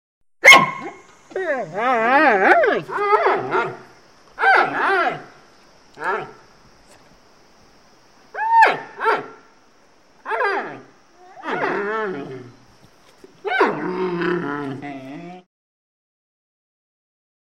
Звуки панды
Звук лая и рычания панды